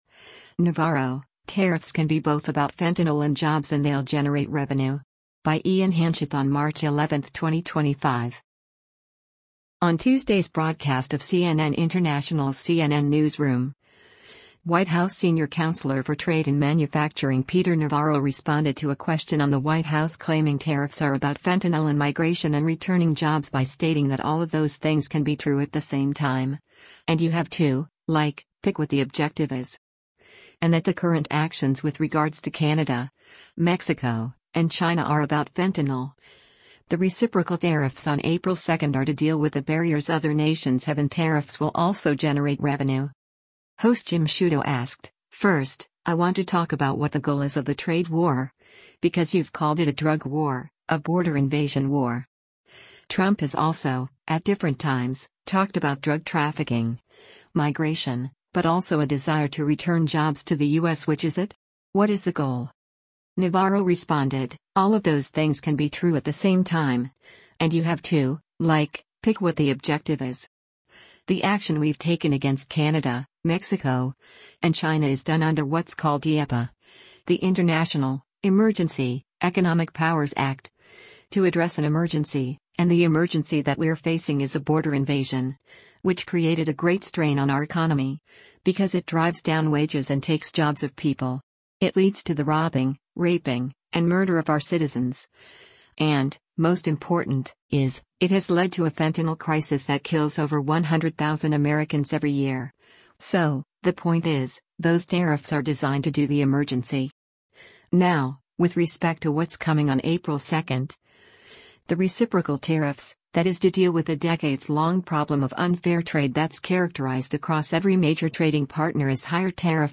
Video Source: CNN International
Host Jim Sciutto asked, “First, I want to talk about what the goal is of the trade war, because you’ve called it a drug war, … a border invasion war. Trump has also, at different times, talked about drug trafficking, migration, but also a desire to return jobs to the U.S. Which is it? What is the goal?”